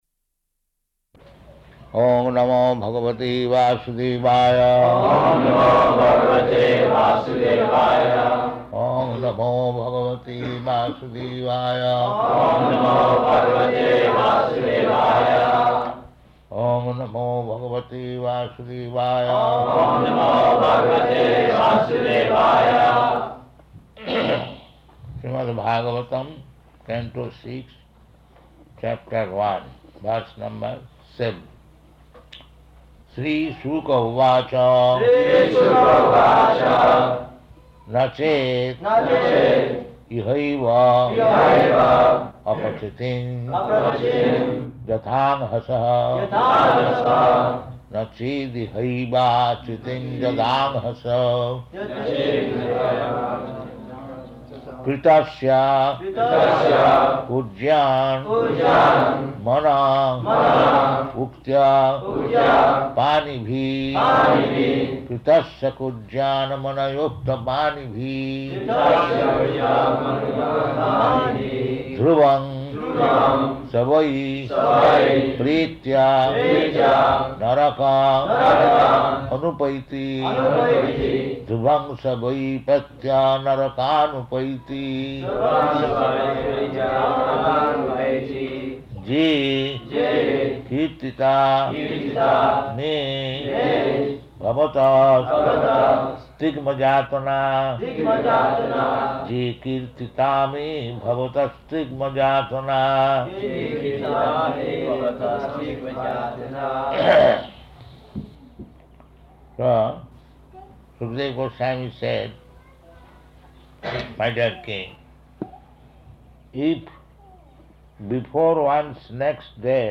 Śrīmad-Bhāgavatam 6.1.7 --:-- --:-- Type: Srimad-Bhagavatam Dated: May 8th 1976 Location: Honolulu Audio file: 760508SB.HON.mp3 Prabhupāda: Oṁ namo bhagavate vāsudevāya.